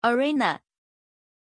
Pronunția numelui Irina
pronunciation-irina-zh.mp3